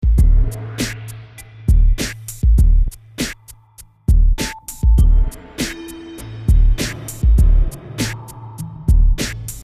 短小的音乐片段。
标签： 背景 Musicfragment 斯派西 合成器 节奏 完成 简介 贝斯 间奏曲
声道立体声